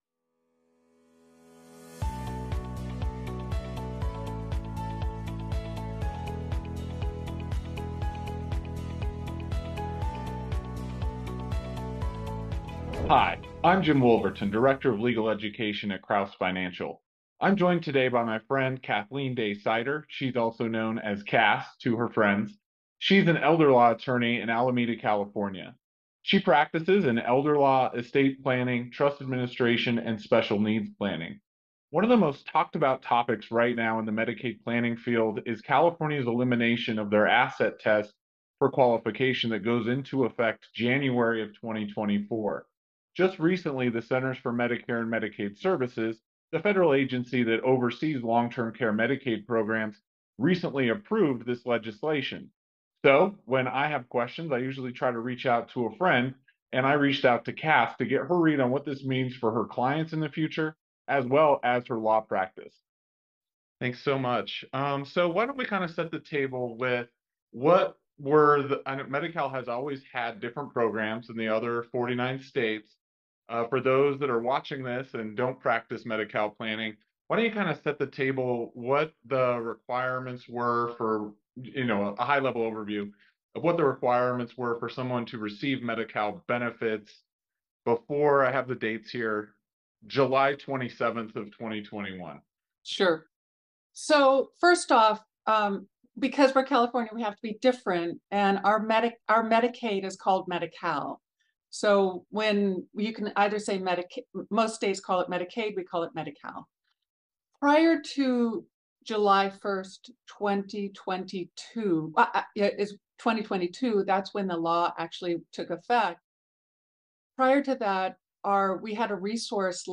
During this interview, we will discuss: